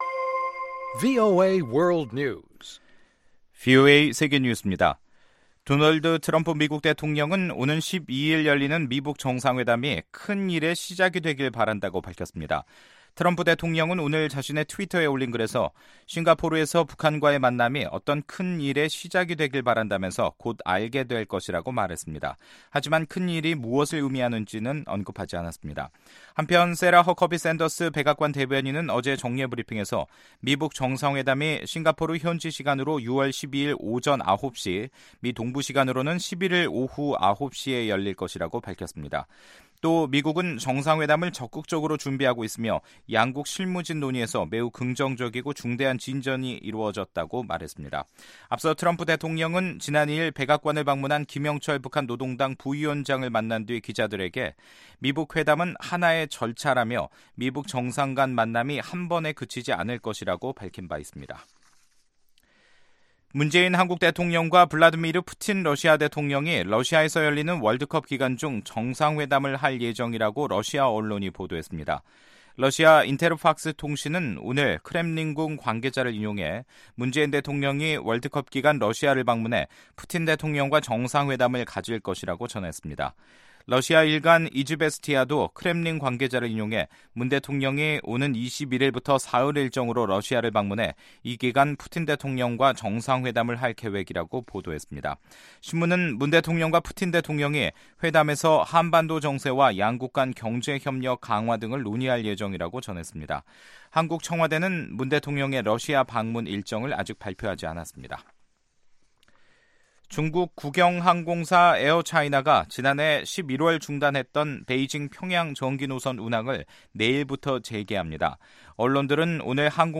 VOA 한국어 간판 뉴스 프로그램 '뉴스 투데이', 2018년 6월 5일 3부 방송입니다. 미국은 북한이 비핵화 할 때까지 제재를 해제하지 않겠다는 입장을 거듭 밝혔습니다. ‘뉴스해설’에서는 미북 정상회담 준비를 폼페오 국무장관이 주도하면서 볼튼 백악관 보좌관은 배제된 분위기라는 미국 언론의 보도를 소개합니다.